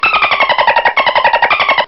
دانلود آهنگ دلفین 6 از افکت صوتی انسان و موجودات زنده
دانلود صدای دلفین 6 از ساعد نیوز با لینک مستقیم و کیفیت بالا
جلوه های صوتی